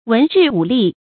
文治武力 注音： ㄨㄣˊ ㄓㄧˋ ㄨˇ ㄌㄧˋ 讀音讀法： 意思解釋： 以文治國的盛績與以武禁暴的偉力。